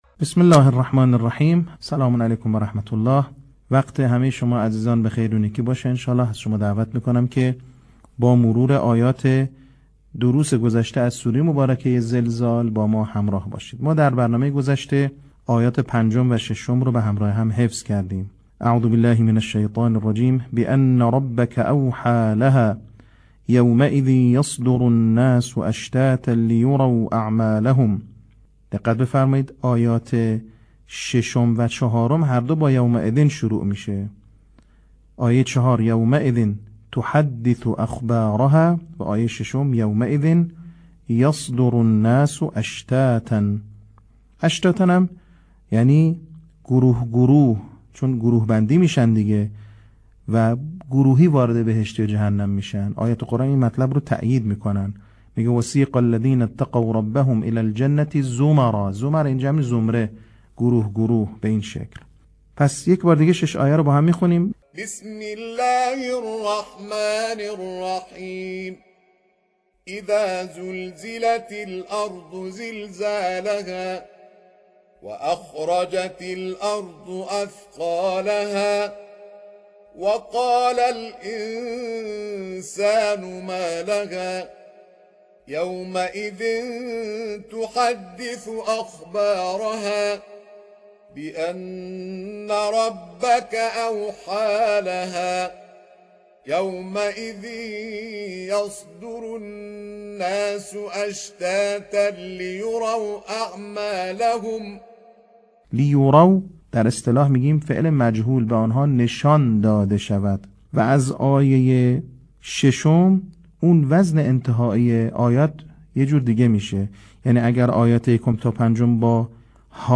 صوت | بخش چهارم آموزش حفظ سوره زلزال